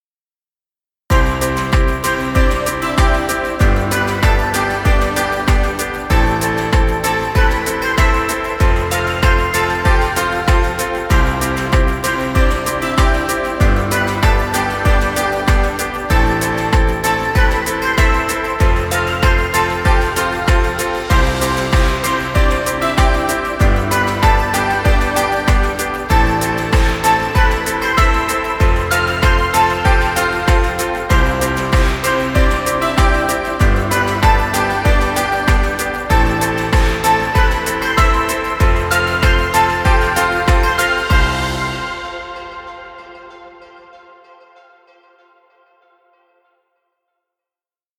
Children music. Background music Royalty Free.